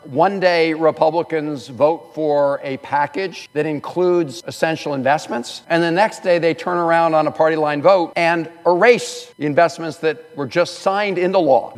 Maryland U.S. Senator Chris Van Hollen spoke on the floor of the Senate on the rescission package that cuts $9 billion dollars from many government-supported programs.  The bill passed the Senate 51-48, but Van Hollen noted Republican members of congress reversed what they had just voted to pass less than two weeks ago…